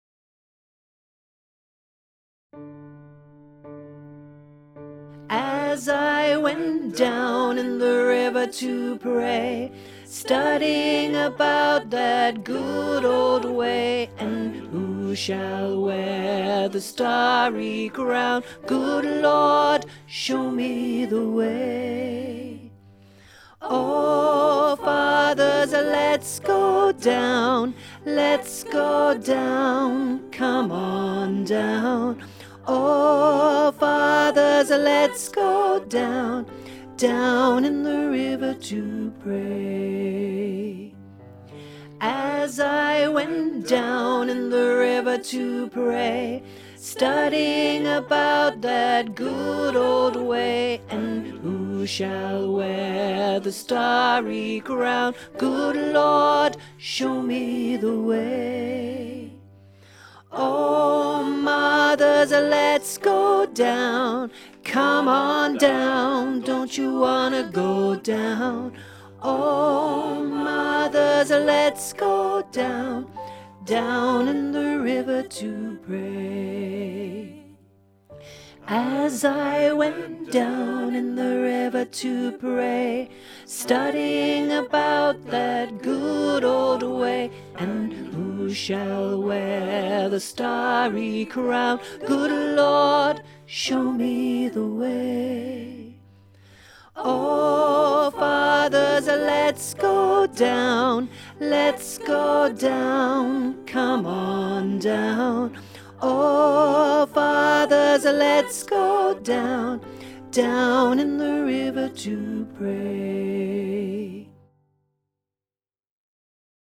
35 Down in the River to Pray (Tenor learning track)
Genre: Choral.